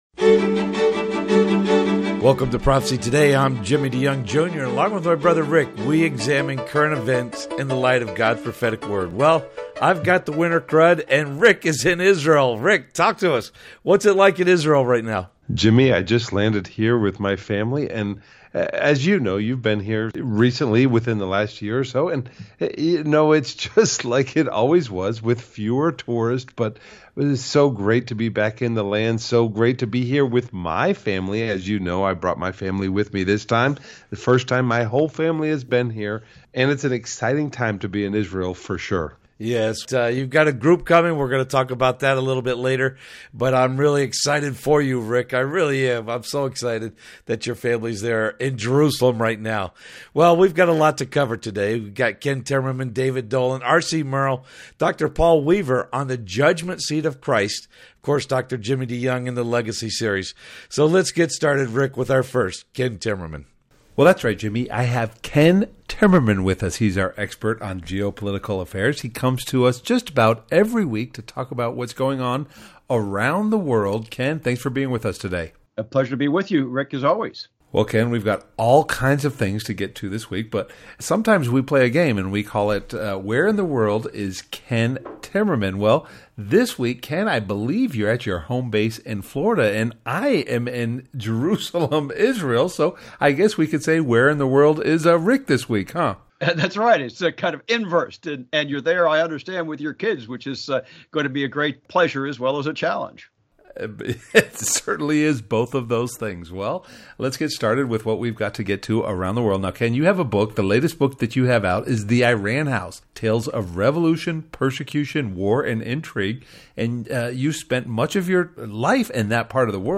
and ‘Prophecy Partners’ on the Prophecy Today Radio Broadcast heard on over 400 stations around the world…